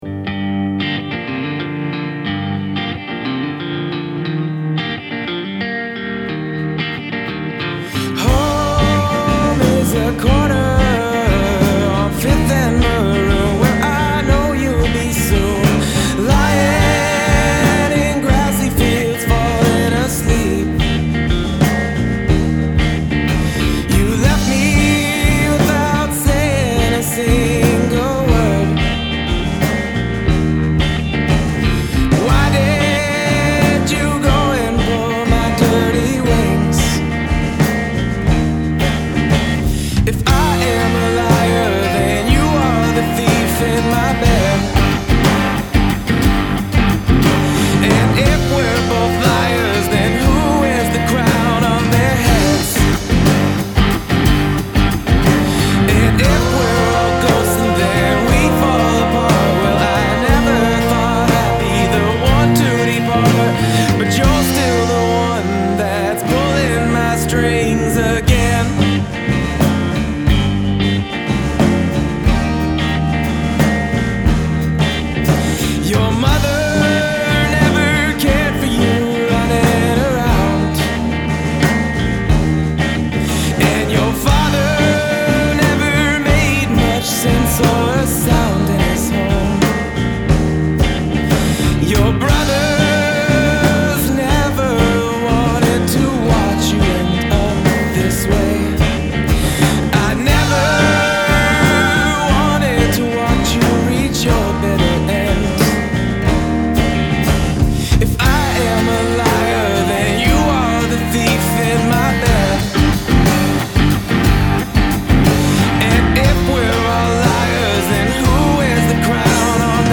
Evil Man LiveEvil Man (Reprise) live